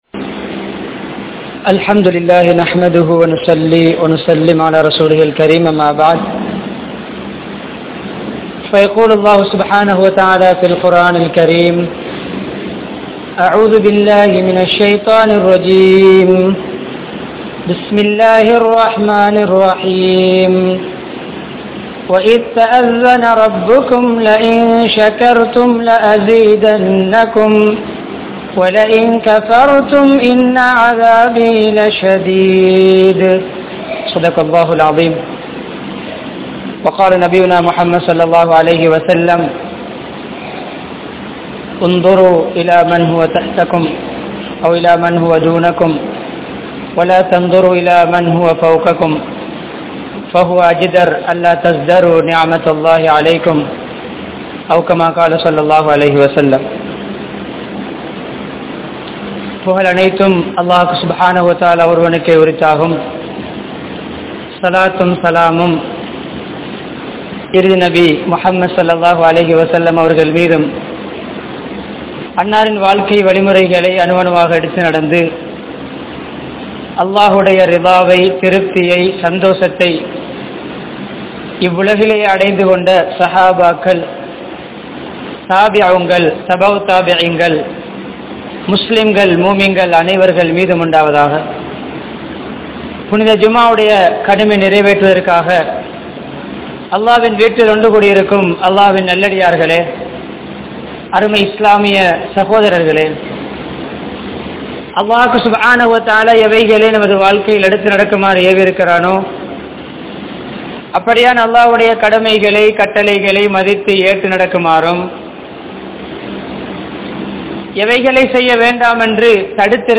Allah Thanthulla Arutkodaihal (அல்லாஹ் தந்துள்ள அருட்கொடைகள்) | Audio Bayans | All Ceylon Muslim Youth Community | Addalaichenai
Gothatuwa, Jumua Masjidh